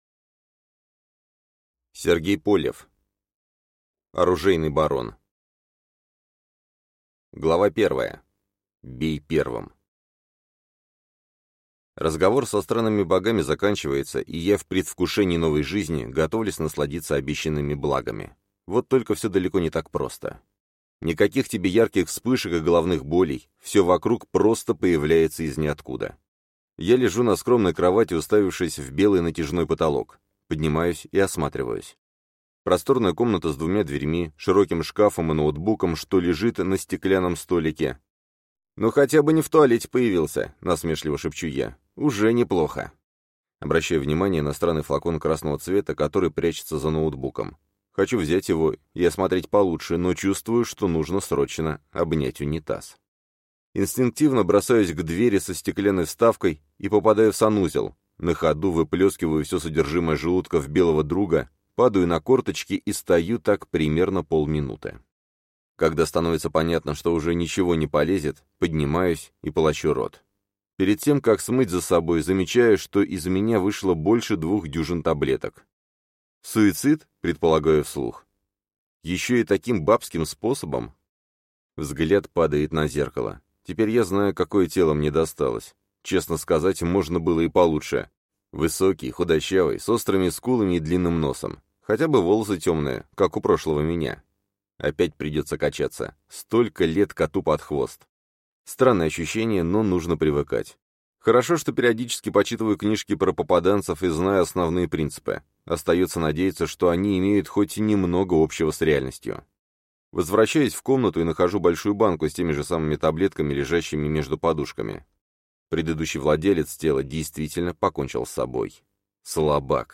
Аудиокнига Оружейный Барон | Библиотека аудиокниг
Aудиокнига